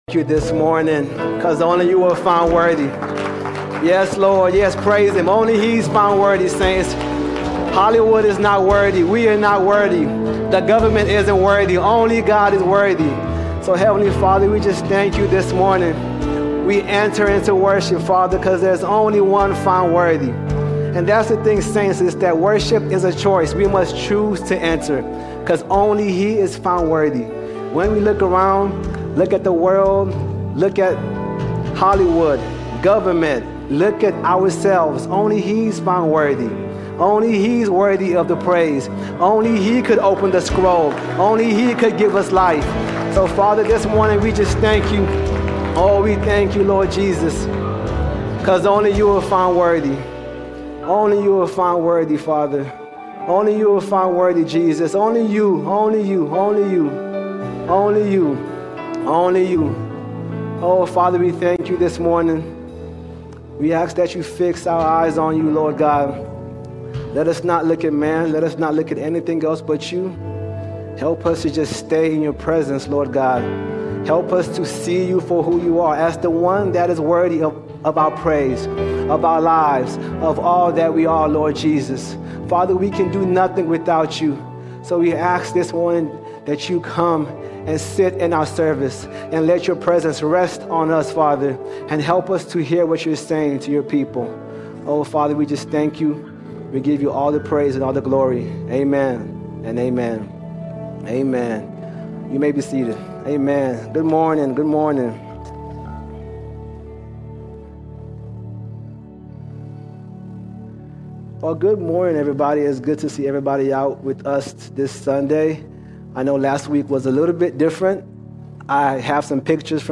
2 February 2026 Series: Sunday Sermons All Sermons Resurrection Now Resurrection Now The resurrection of Jesus is not only a future hope but a present power.